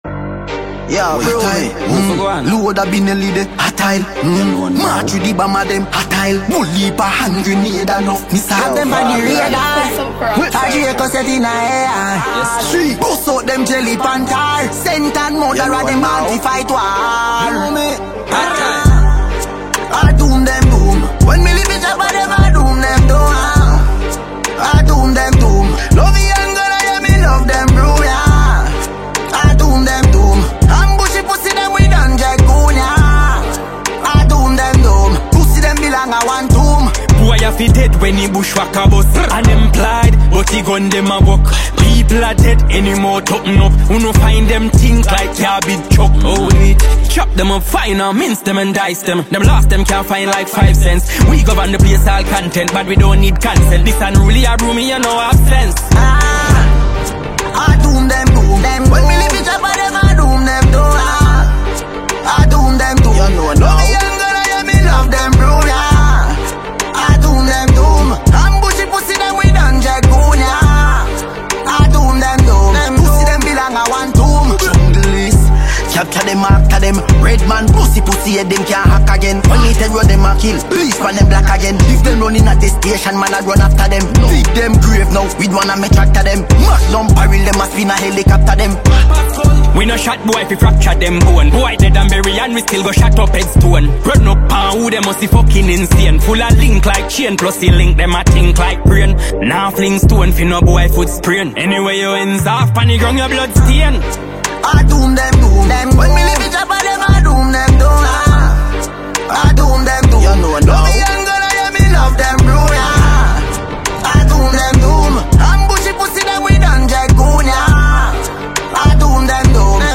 dancehall rhythms and catchy lyrics